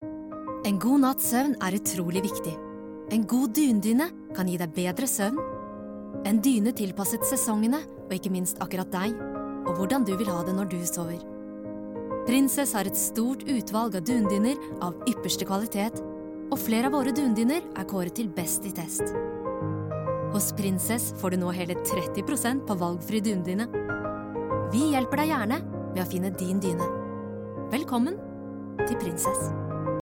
Female
Friendly, Confident, Character, Corporate, Energetic, Natural, Warm, Engaging
Microphone: SM Pro Audio MC01